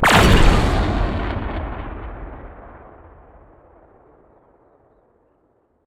weapons
torpidoa.wav